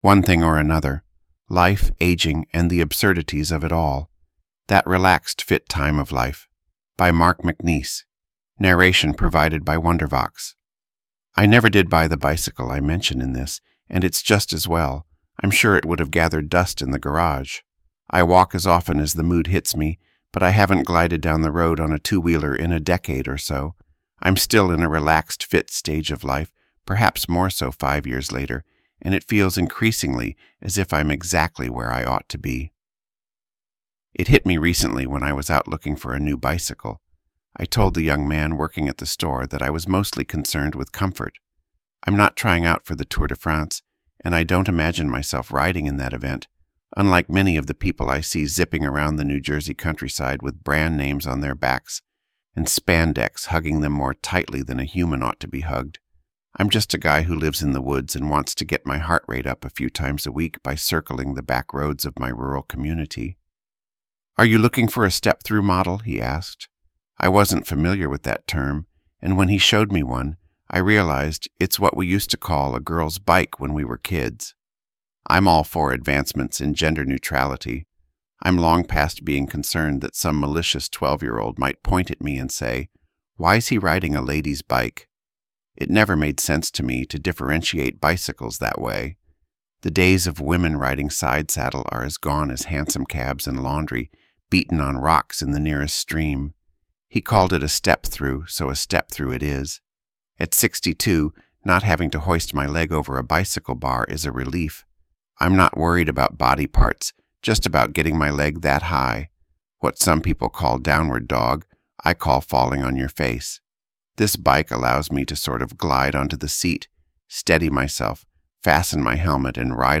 Narration provided by Wondervox